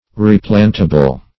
Replantable \Re*plant"a*ble\ (-?-b'l), a. That may be planted again.